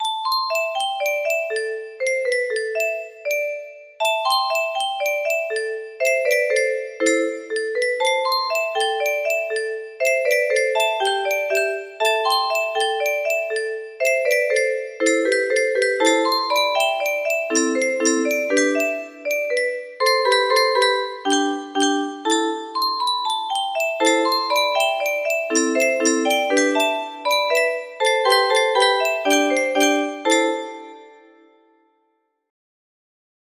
Bedbug Bop music box melody